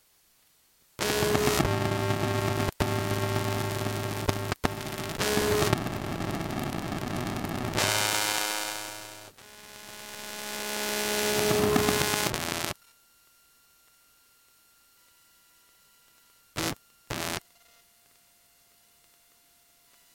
感应线圈录音，2014年9月 " 电话解锁
描述：在最近的手机上解锁锁定屏幕引起的嘈杂静噪。用感应线圈麦克风录音。
标签： 嘈杂 静噪 手机 现场记录 感应线圈 科幻 噪声
声道立体声